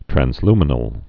(trăns-lmə-nəl, trănz-)